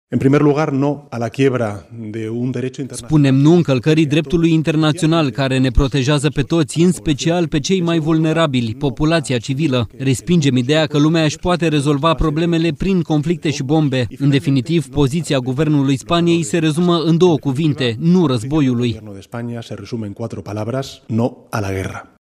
Premierul Spaniei, într-o conferință de presă la Guvernul Spaniei: „Poziția Guvernului Spaniei se rezumă în două cuvinte: nu războiului”